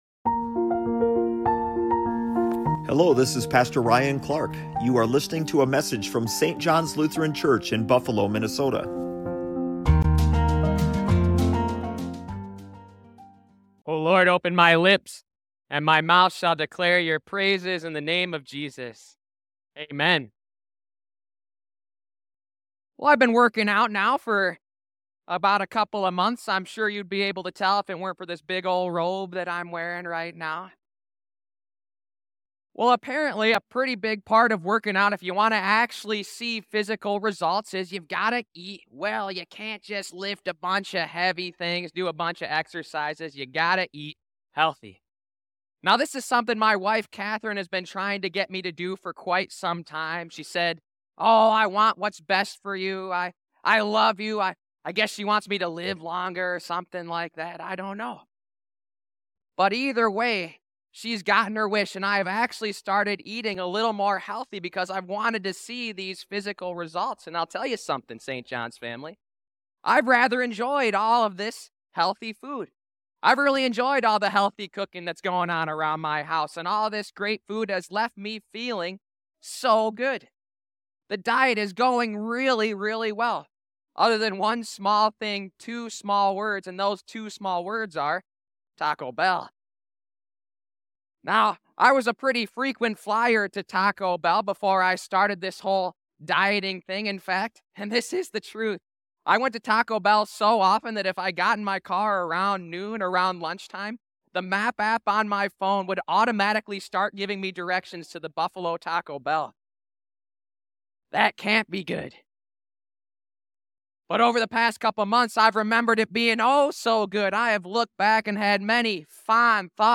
St. John's Lutheran Church